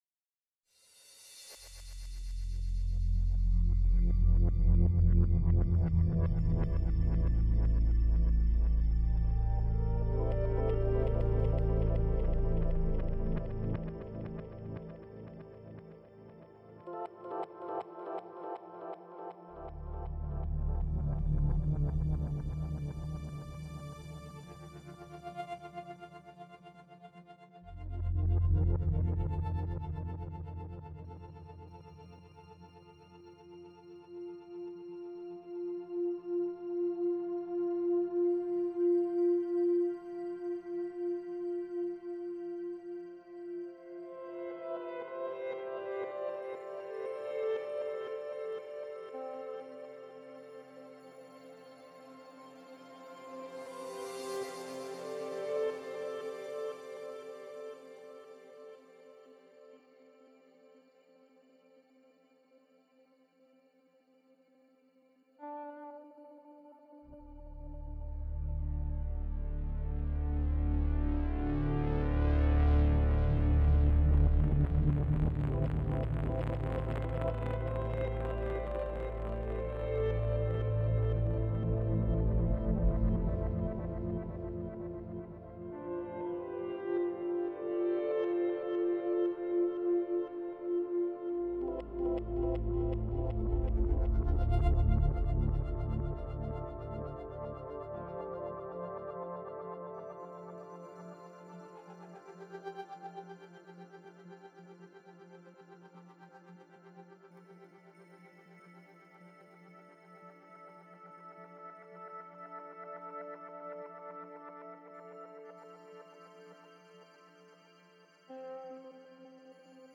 Ausser-mir-iner-ich_Soundscape_Version-1.mp3